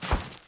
snowfall2.wav